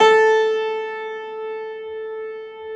53c-pno13-A2.wav